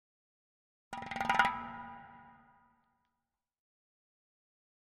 Drums Short Drumming 1 - Very Fast Hits - Thin Drum